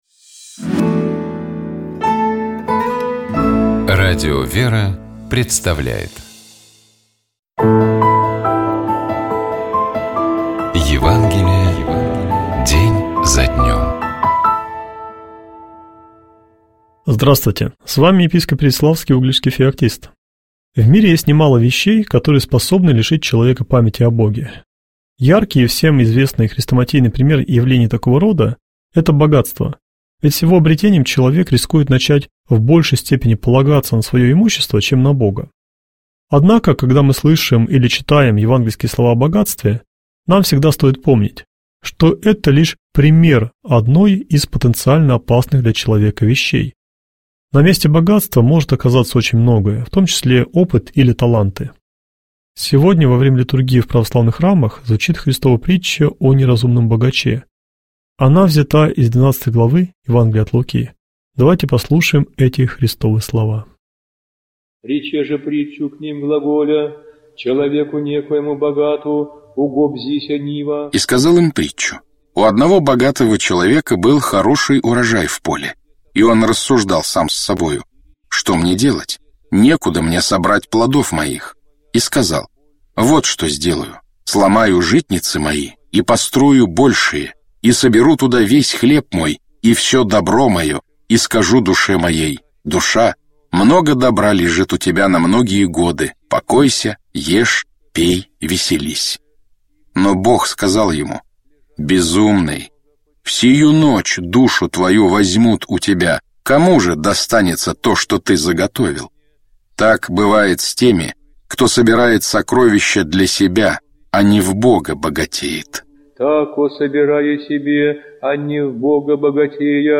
епископ Феоктист ИгумновЧитает и комментирует епископ Переславский и Угличский Феоктист